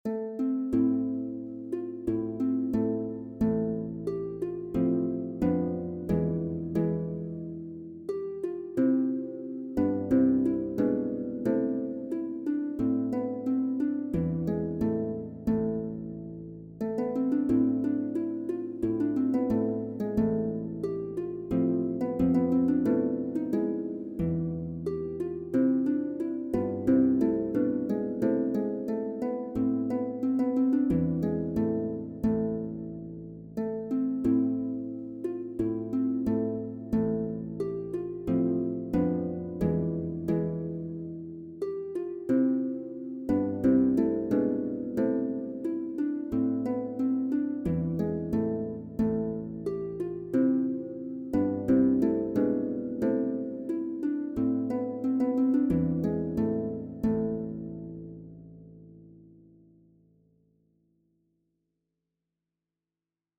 TORBAN/BAROQUE LUTE SOLOS